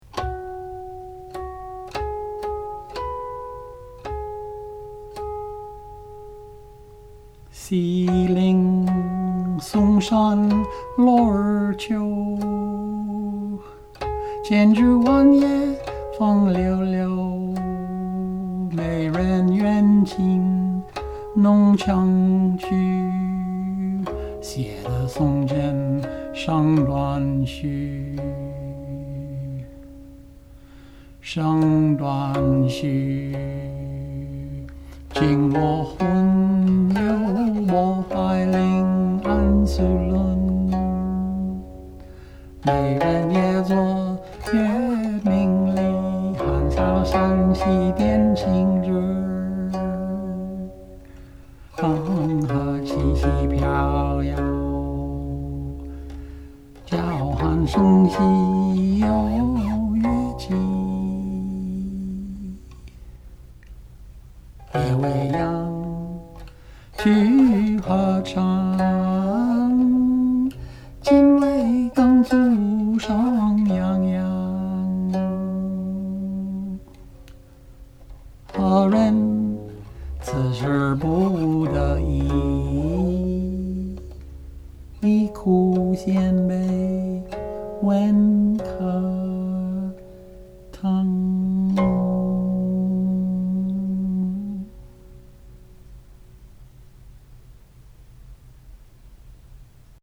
8. Qin songs and other duets
my recording 聽錄音; notice the heterophony.